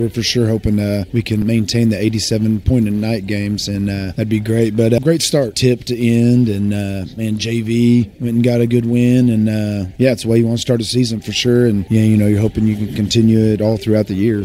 During Saturday morning's Coach's Breakfast Broadcast on KWON,